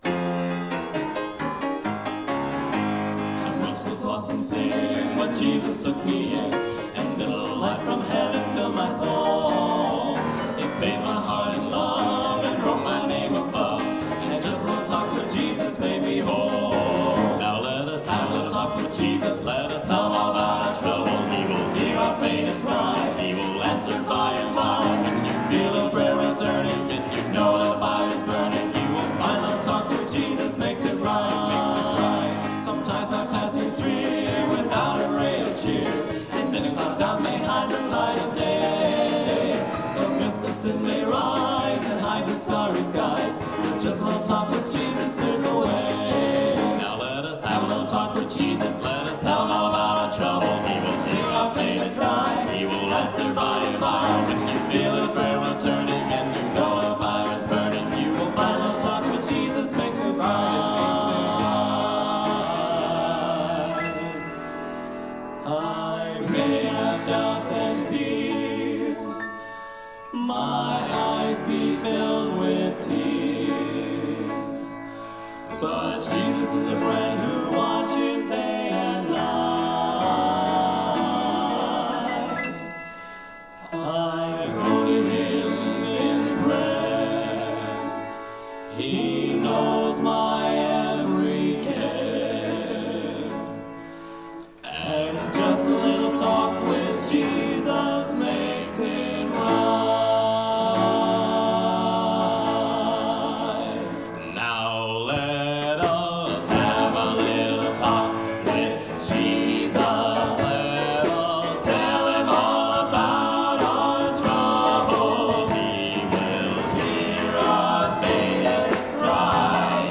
Genre: Gospel | Type: Specialty